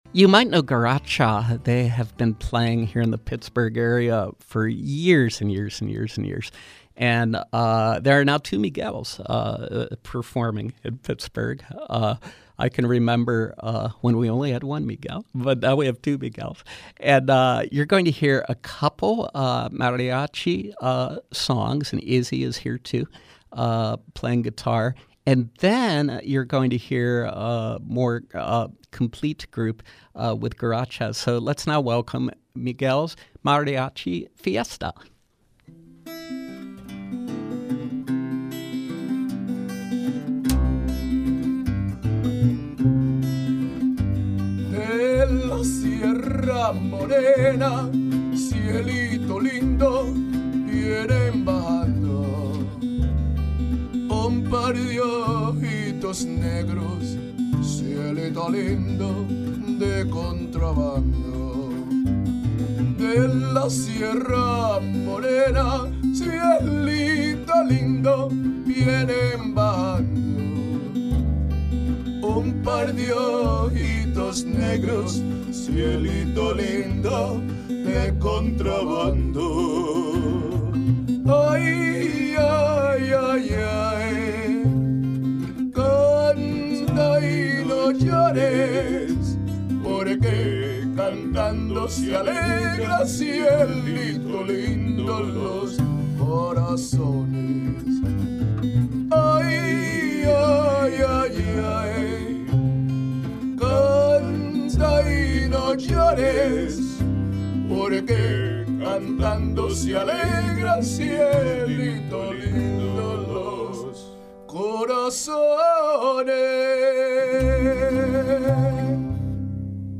perform traditional and dance Latino music.